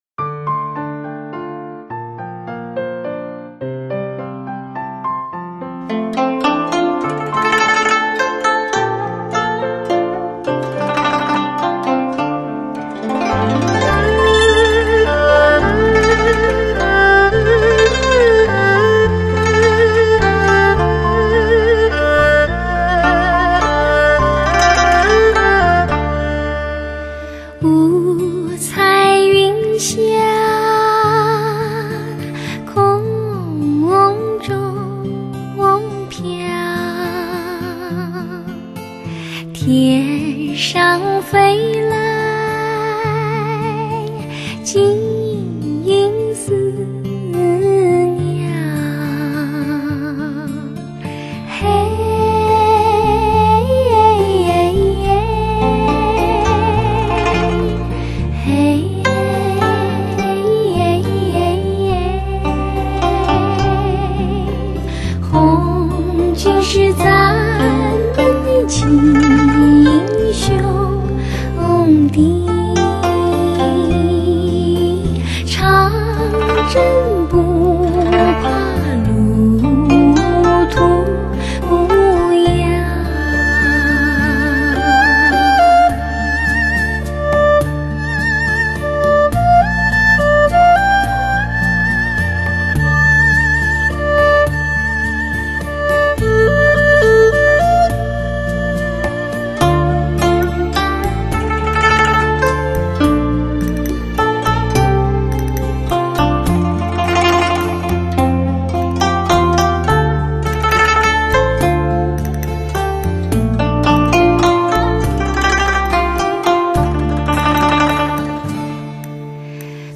版    本：32Bit数码录音之作
中国发烧之女声极品。
一个有典雅味道，清纯美感的歌喉，
录音采用少有的顶级电子管麦克风制作该唱片，
女声与拌奏乐器的音色听来有如丝绒般光润甜美，
更能体会到柔和延展的超高频。